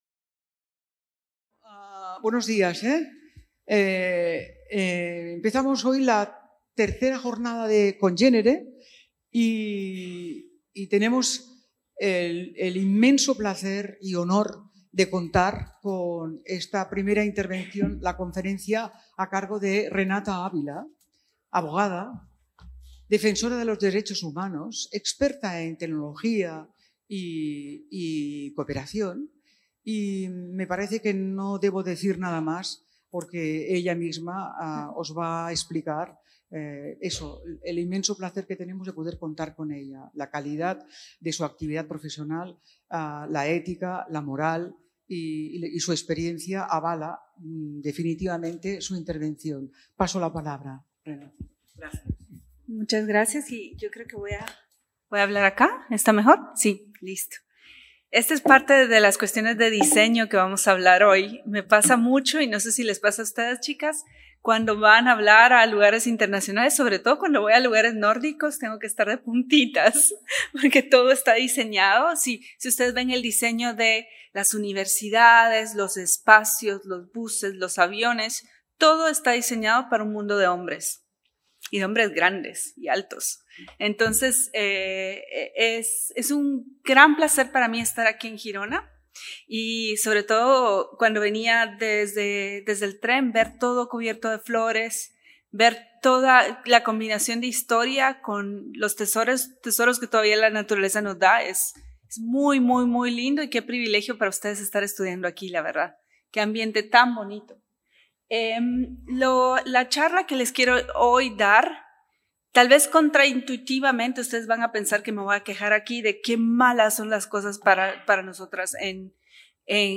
Conferència